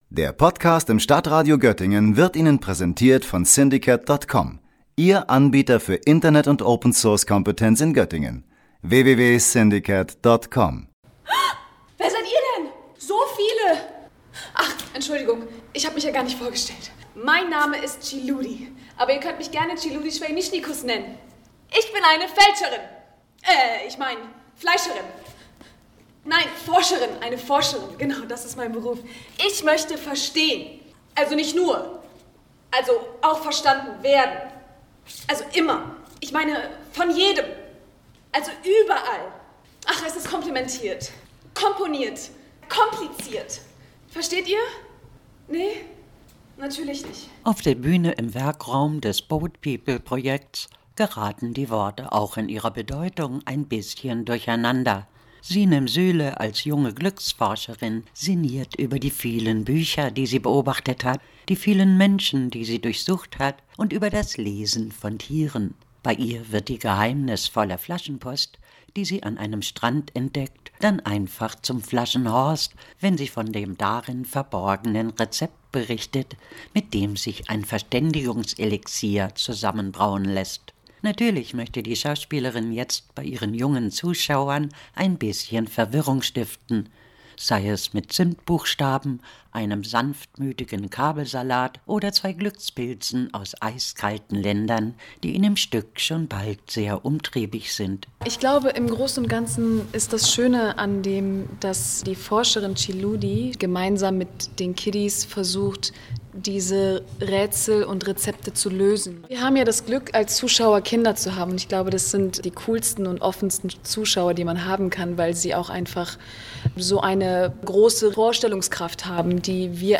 O-Ton 1, Einspieler „HÄ?!, 35 Sekunden